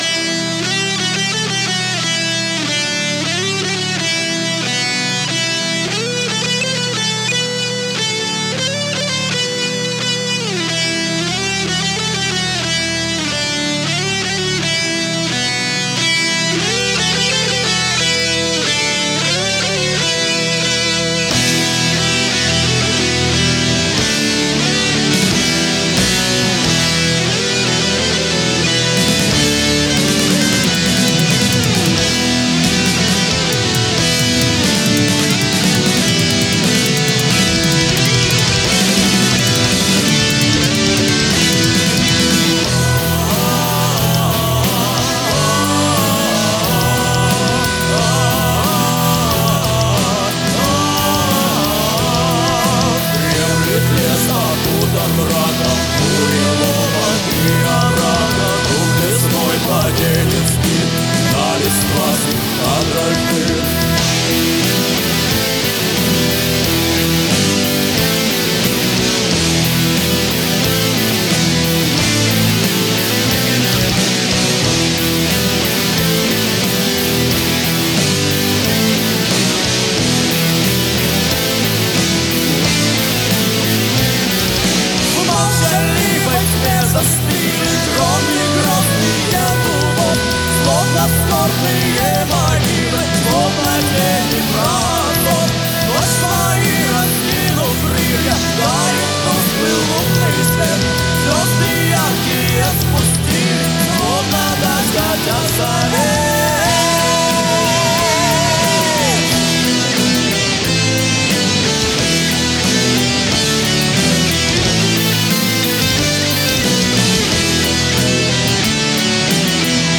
Pagan Folk Metal